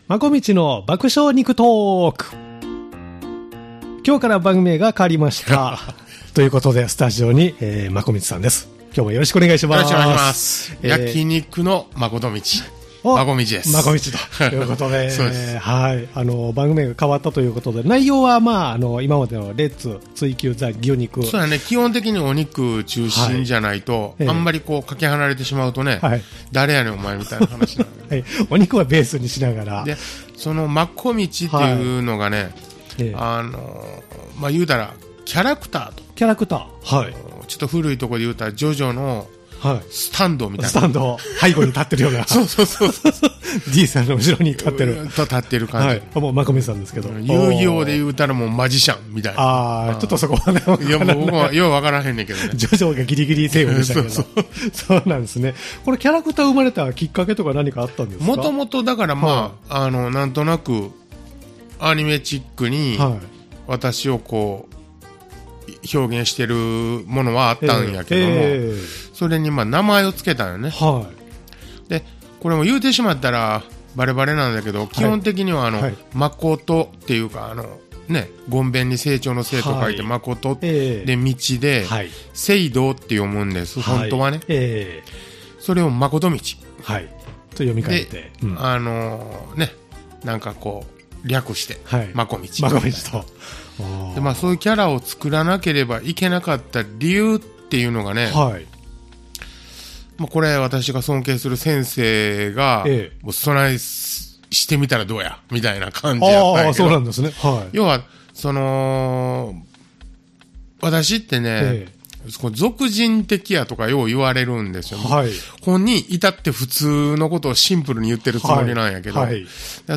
お肉をテーマにわいわいお送りするポッドキャスト番組